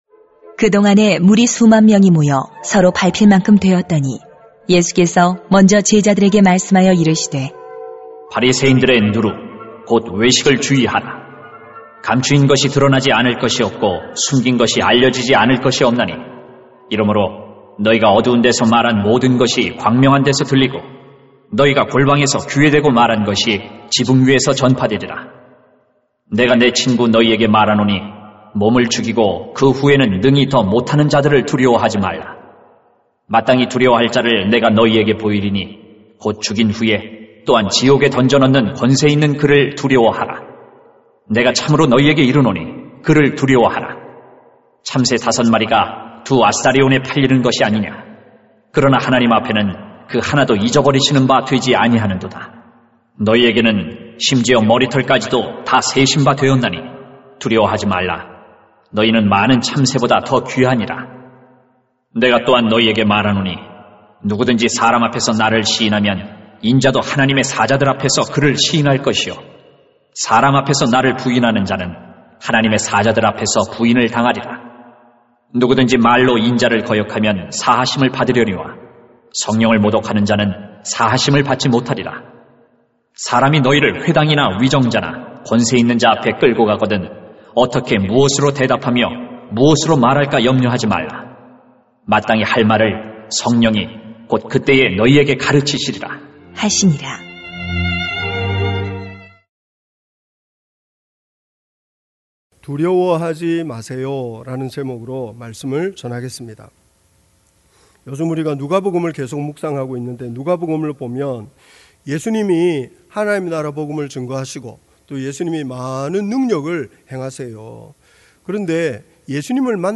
[눅 12:1-12] 두려워하지 마세요 > 주일 예배 | 전주제자교회